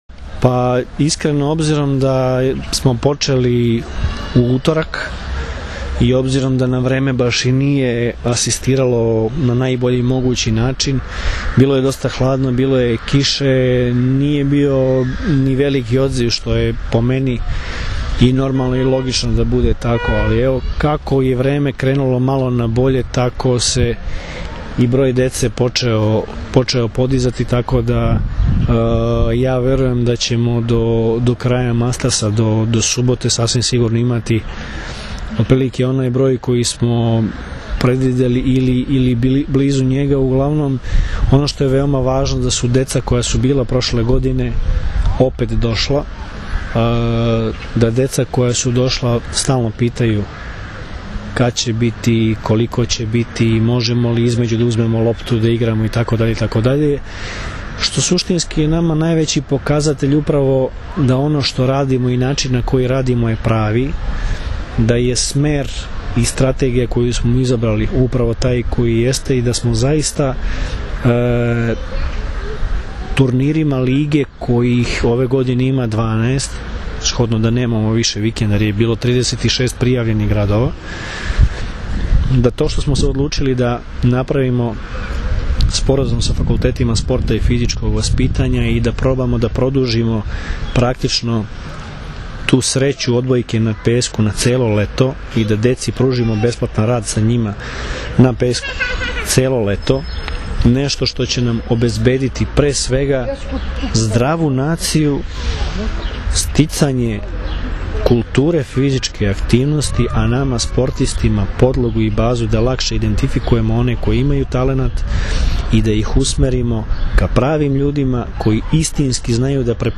IZJAVA VLADIMIRA GRBIĆA